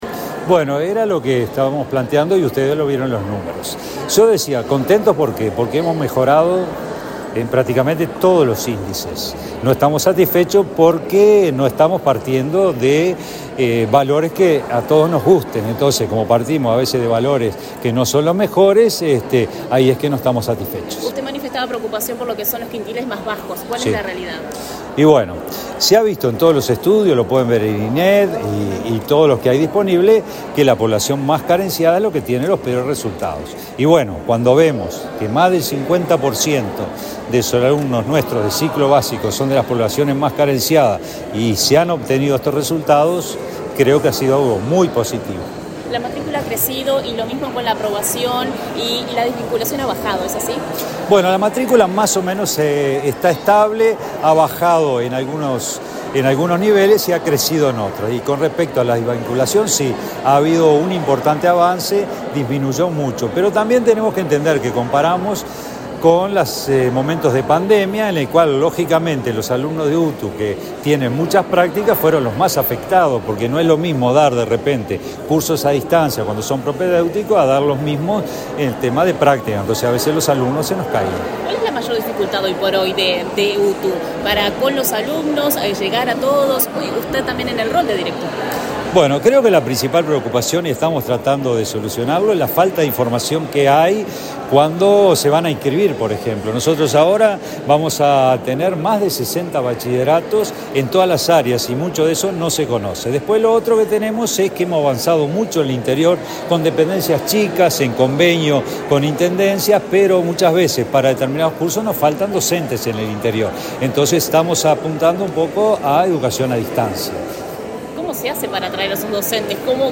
Declaraciones del director general de UTU, Juan Pereyra
La presidenta de la Administración Nacional de Educación Pública (ANEP), Virginia Cáceres, y el director general de Educación Técnico Profesional (UTU), Juan Pereyra, participaron, este miércoles 6 en Montevideo, en la presentación del Monitor Educativo de Enseñanza Media Técnico Profesional 2022-2023. Luego Pereyra dialogó con la prensa.